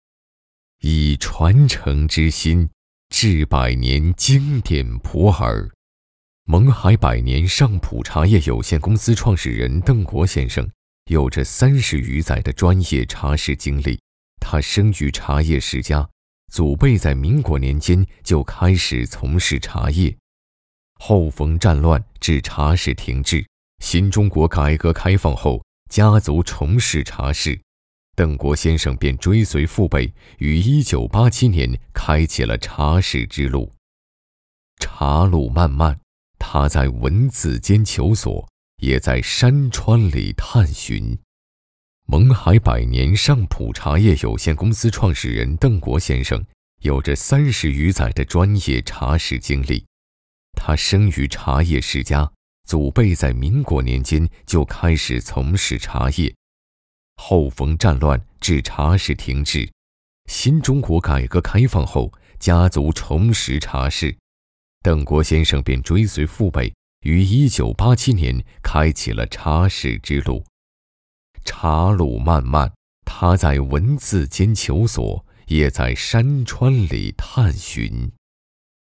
娓娓道来 企业宣传配音
磁性韵味、娓娓道来讲述男音，擅长纪录片解说、旁白讲述风格、企业宣传片解说题材。